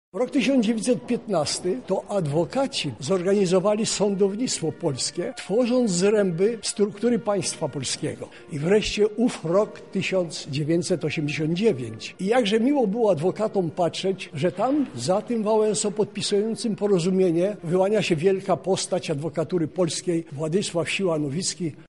Swoją refleksją na temat wystawy podzielił się z zebranymi sędzia Ferdynand Rymarz.